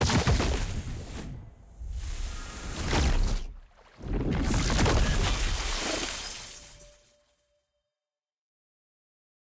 sfx-exalted-rolling-ceremony-multi-anim.ogg